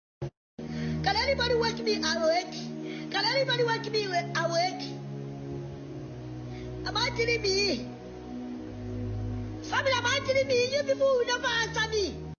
Can somebody wake me awake (Comedy sound)
Can somebody wake me awake (Comedy sound) c Download mp3 here Sponsored ad Comedy funny sound